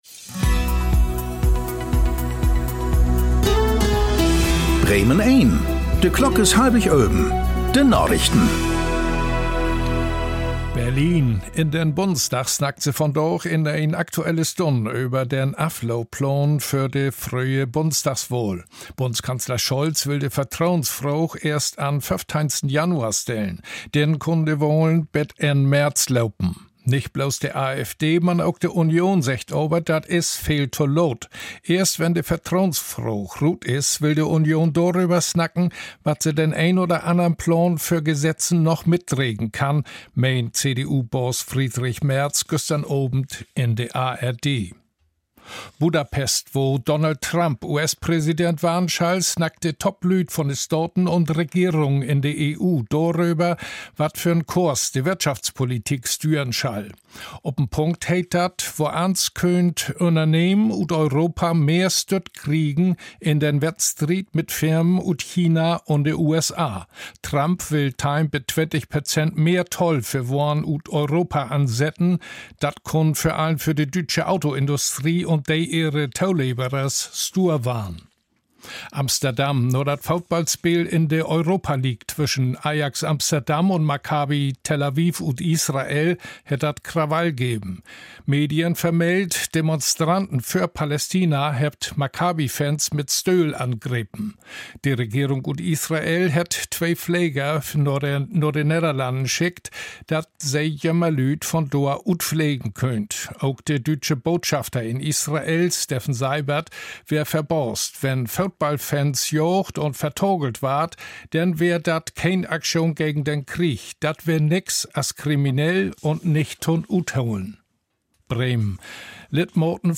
Aktuelle plattdeutsche Nachrichten werktags auf Bremen Eins und hier für Sie zum Nachhören.